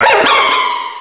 pokeemerald / sound / direct_sound_samples / cries / virizion.aif